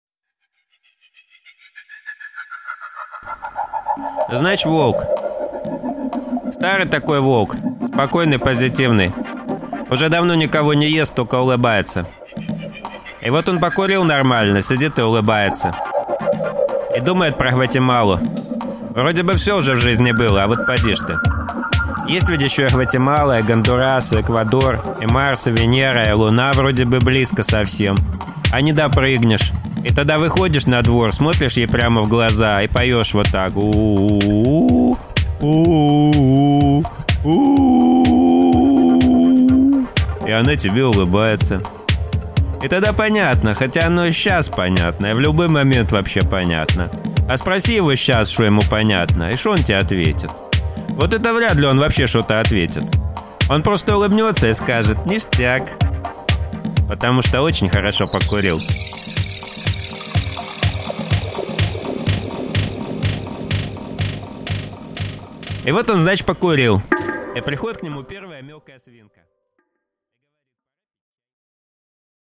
Аудиокнига Волк и семеро свиней | Библиотека аудиокниг
Прослушать и бесплатно скачать фрагмент аудиокниги